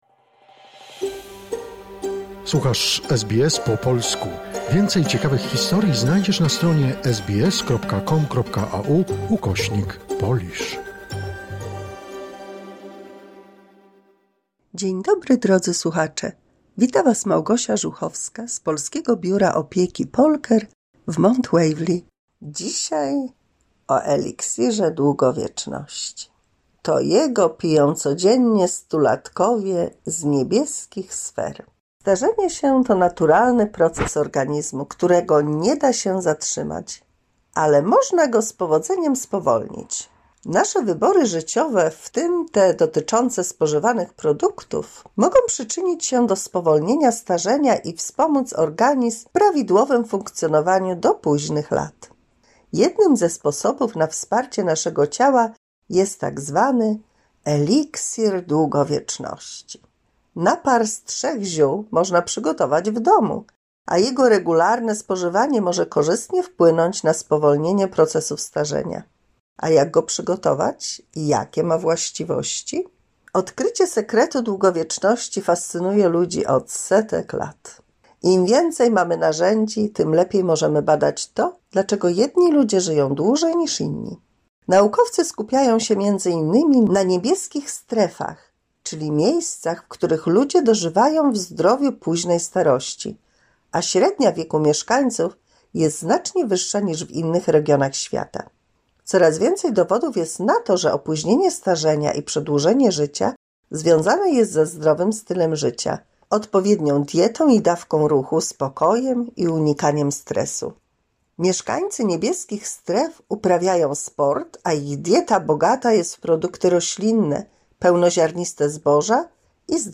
150 mini słuchowisko dla polskich seniorów